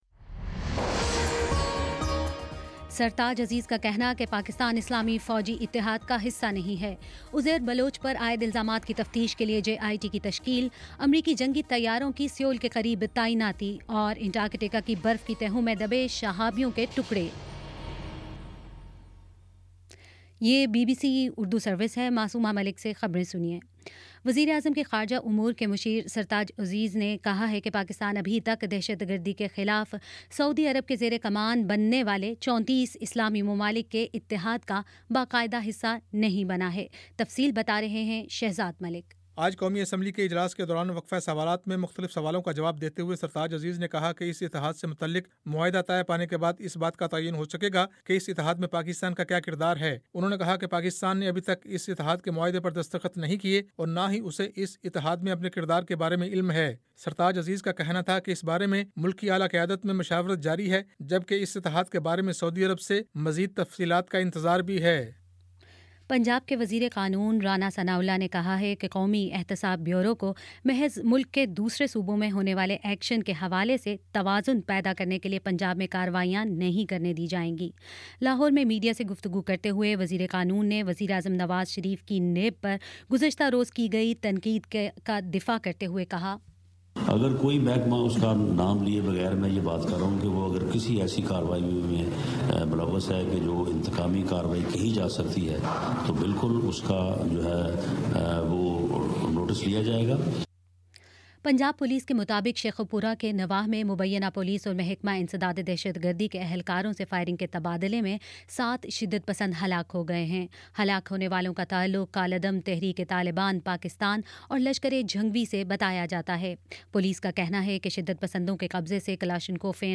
فروری 17 : شام پانچ بجے کا نیوز بُلیٹن